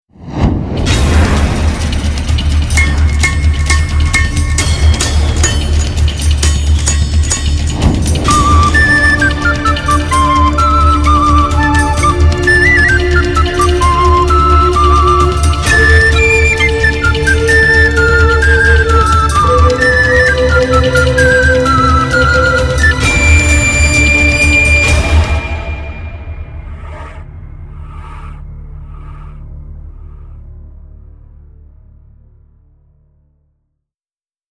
originální znělku